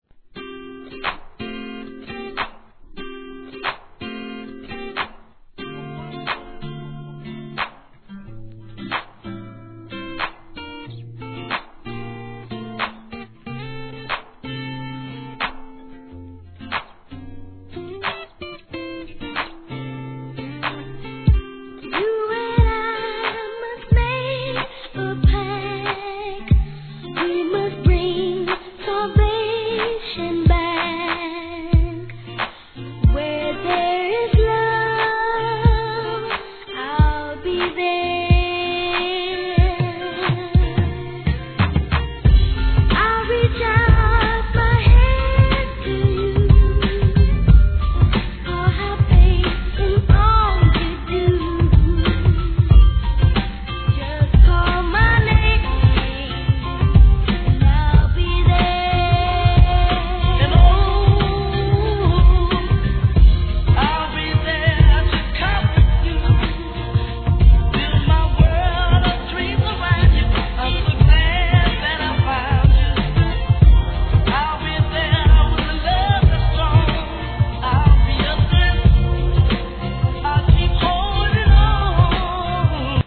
HIP HOP/R&B
ACOUSTIC CLUB RARE MIX